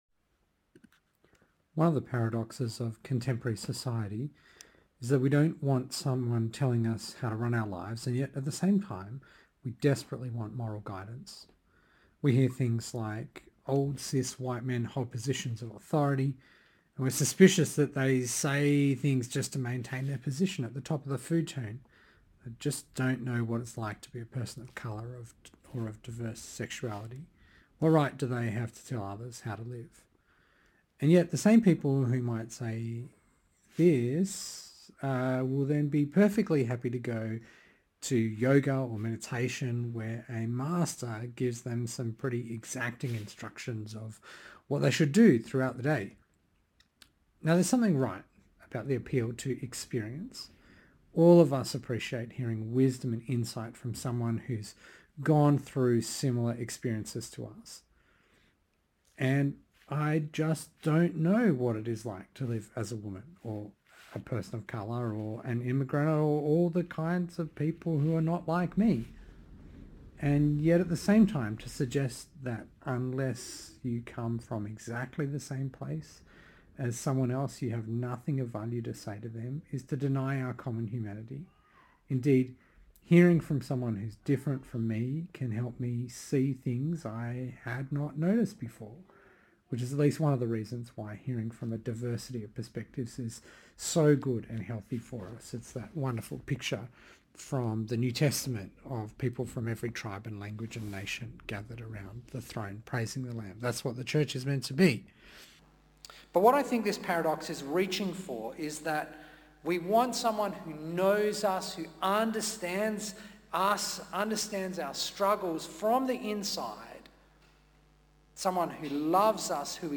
2024 He too shared their humanity Preacher